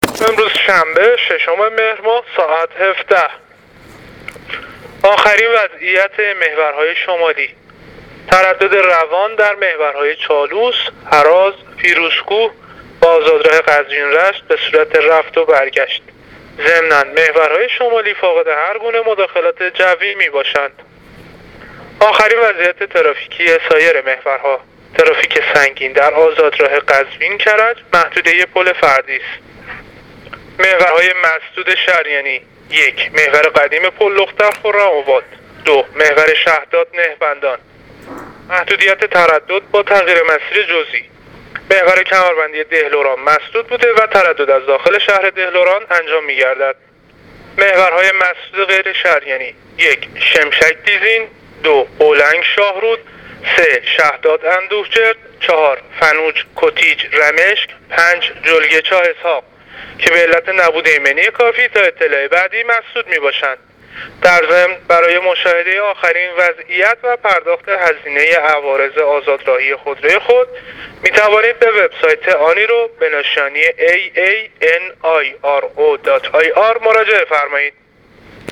گزارش رادیو اینترنتی پایگاه خبری وزارت راه و شهرسازی از آخرین وضعیت ترافیکی جاده‌های کشور تا ساعت ۱۷:۰۰ ششم مهرماه/ ترافیک سنگین در آزادراه قزوین-کرج